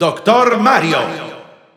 Spanish Announcer announcing Dr. Mario.
Dr._Mario_Spanish_Announcer_SSBU.wav